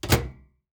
microwave-sound-off